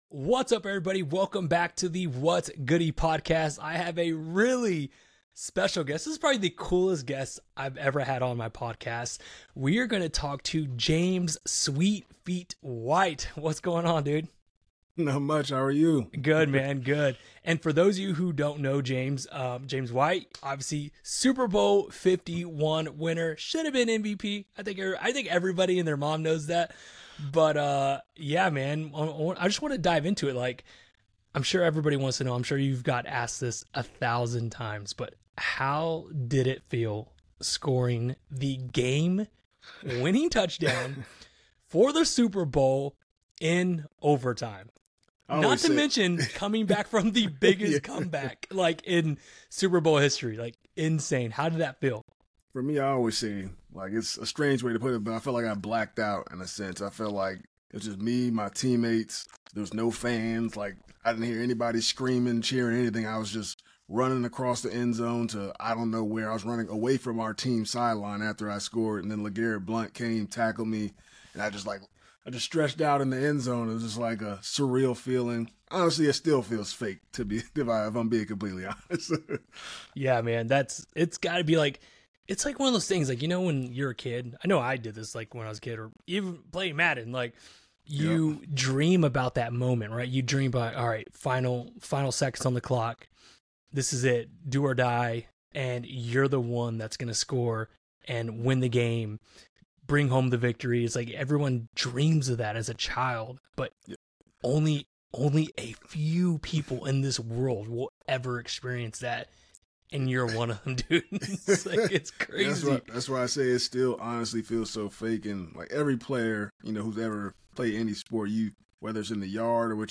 The Whats Goody Podcast is all about real interviews with real people.